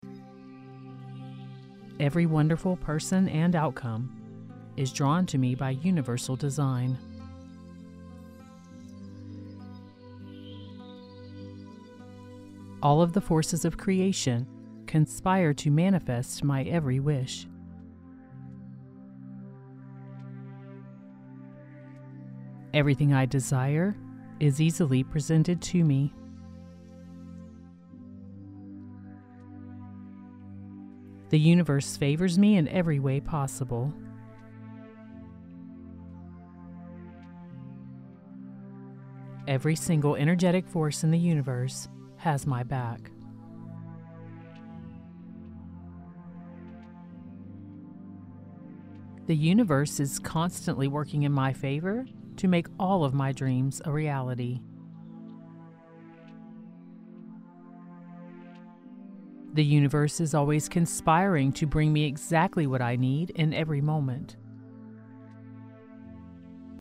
This 1 hour track is filled with 400+ affirmation impressions (63 total affirmations looped) that reinforce the answers to these types of questions in a way that opens your subconscious mind up to new assumptions, new possibilities and new manifestations!